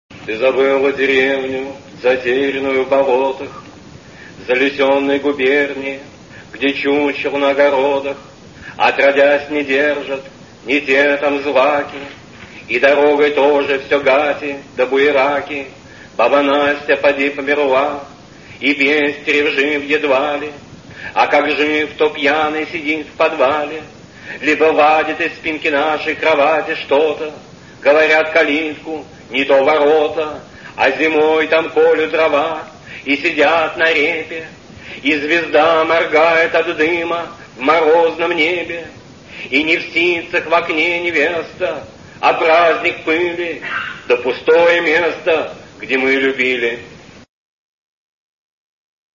18. «Иосиф Бродский (читает автор) – Ты забыла деревню, затерянную в болотах…» /